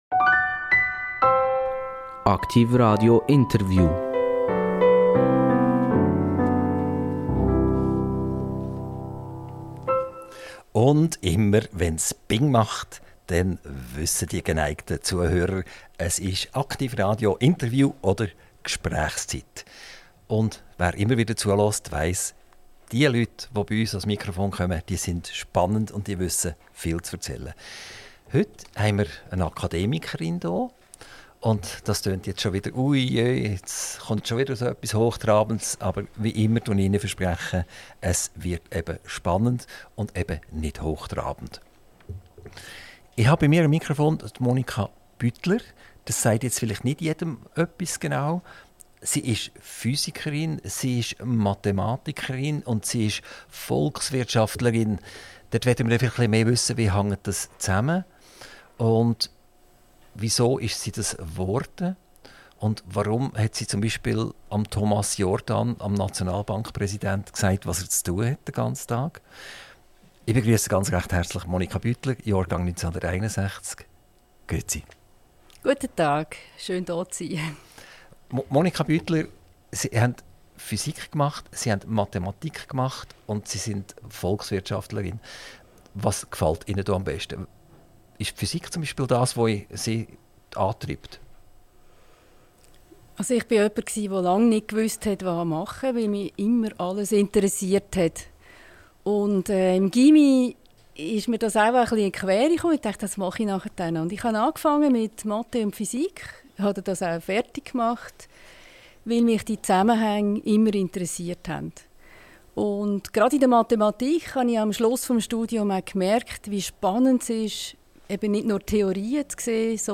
INTERVIEW - Prof. Dr. Monika Bütler - 04.07.2024 ~ AKTIV RADIO Podcast